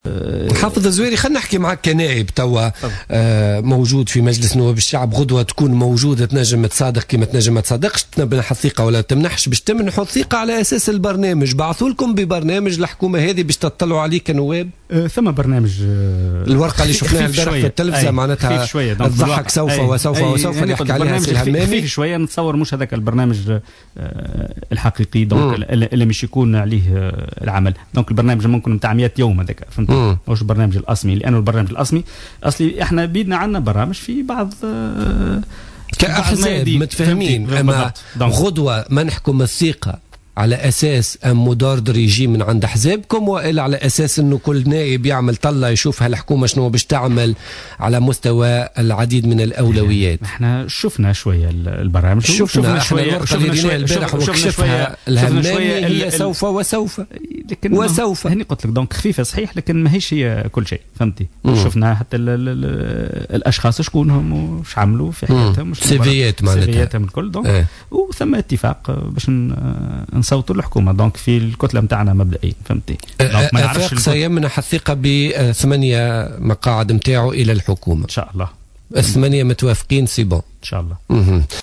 Le député d’Afek Tounes au parlement du peuple, Hafedh Zouari, est revenu lors de son passage sur les ondes de Jawhara FM dans le cadre de l’émission Politica du mardi 3 février 2015, sur la composition du nouveau gouvernement d’Essid.